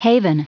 Prononciation du mot haven en anglais (fichier audio)
Prononciation du mot : haven